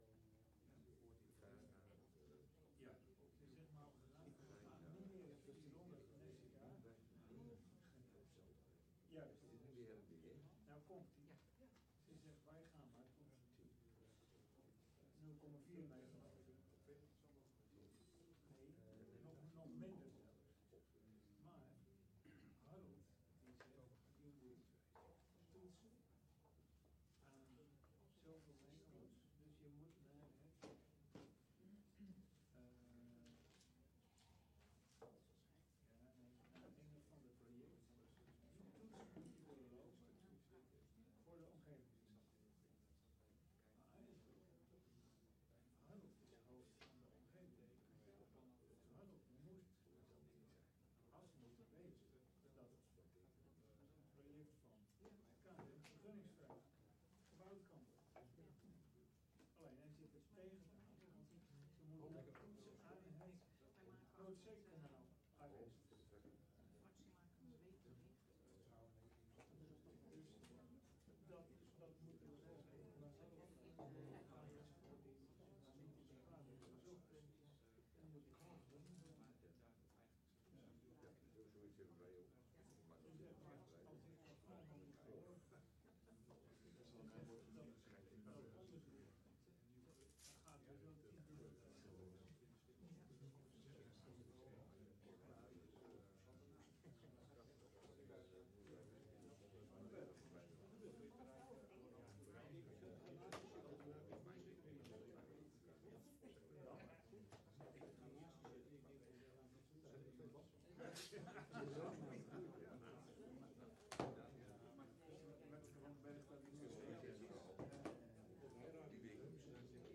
Locatie: Commissiekamer